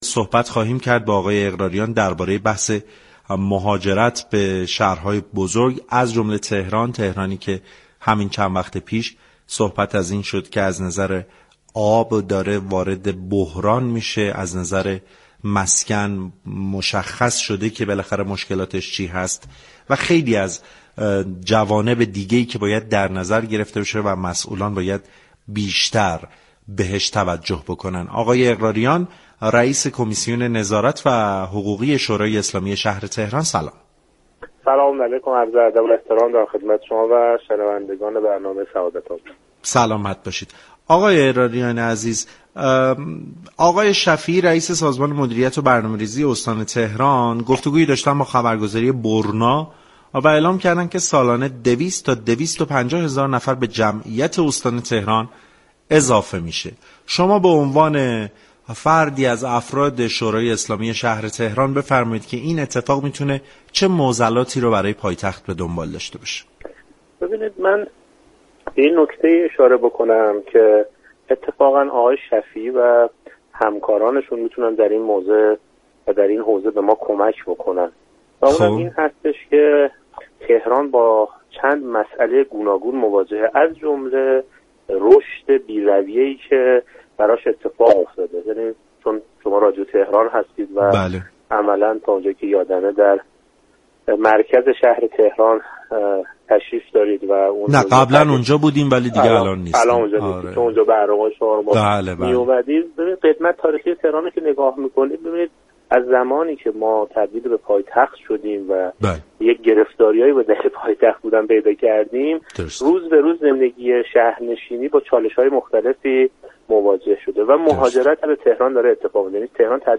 رئیس كمیته نظارت و حقوقی شورای شهر تهران معتقد است: استان تهران از تعارض منافع رنج می‌برد و فرمانداری‌ها برای كسب درآمد هروز به كوچك‌تر شدن تفرجگاه های تهران كمك می‌كنند.